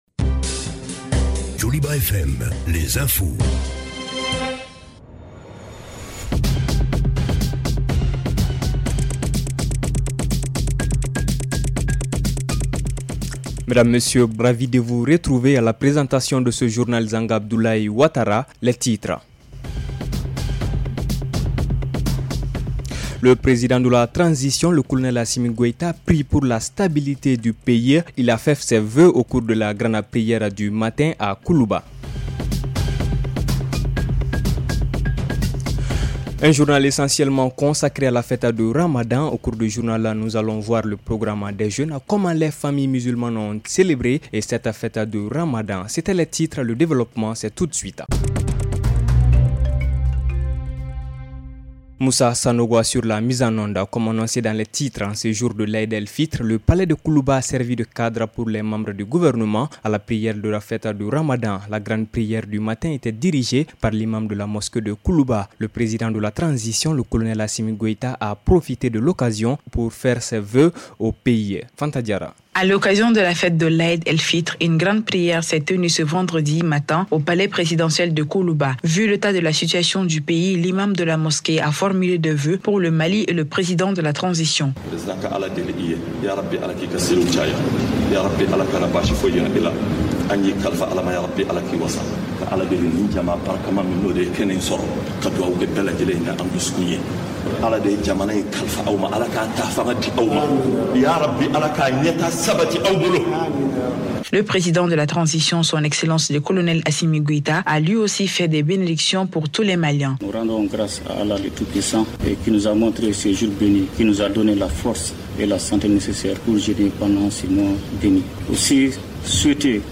REPLAY 21/04 – Le journal en français de 17h30